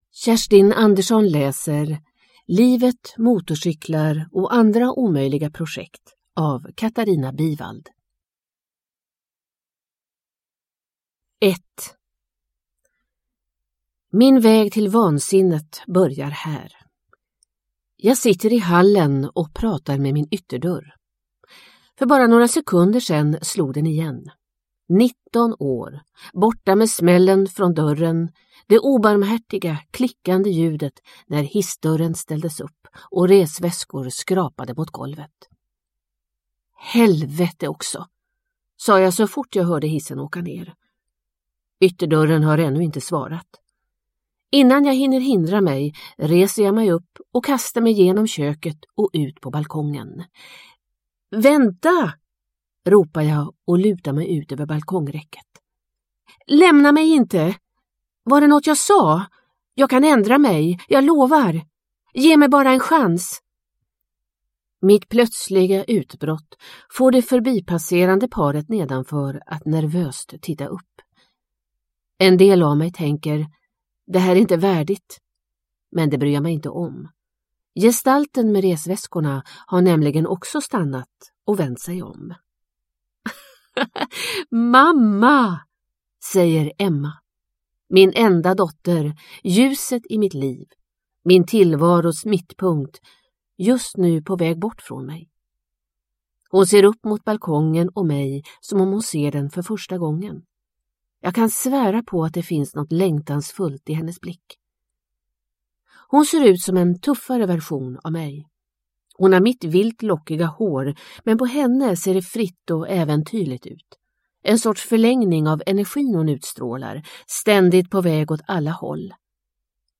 Livet, motorcyklar och andra omöjliga projekt – Ljudbok